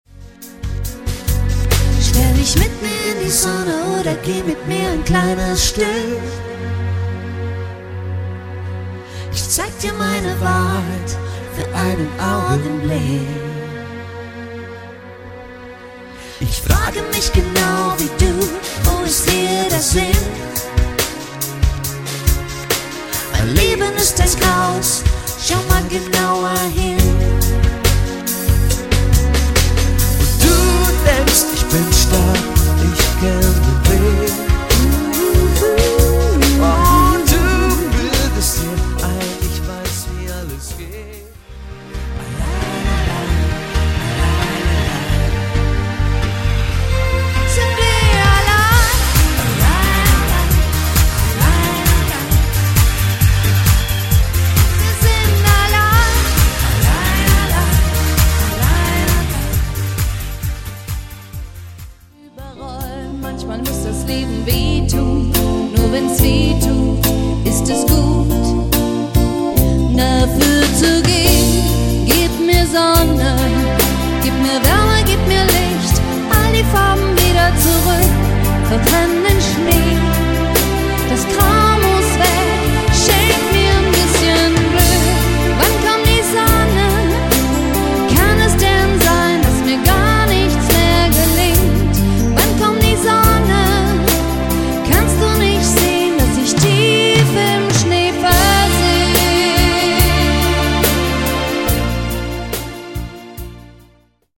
- das DUO klingt bereits wie eine ganze 5-Mann-Band
- ECHTE Live-Musik & Live-Gesang mit 2 SUPER Solostimmen
• Coverband